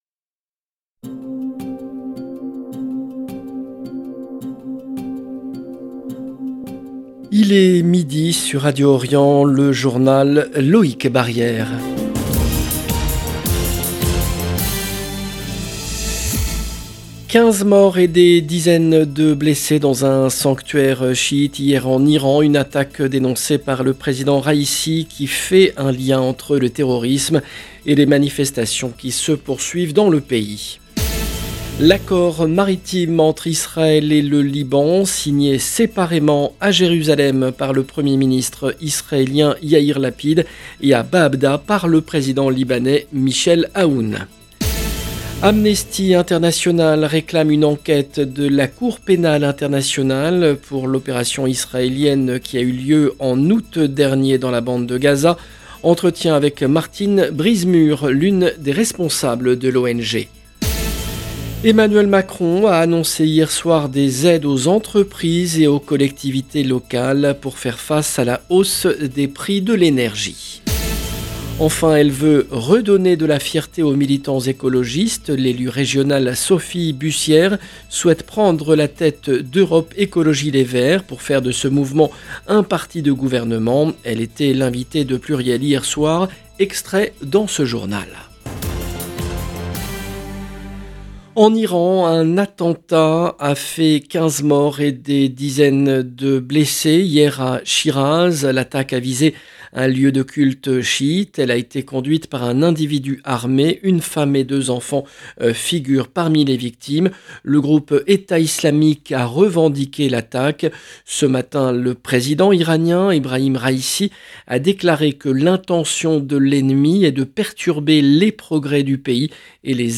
JOURNAL EN LANGUE FRANÇAISE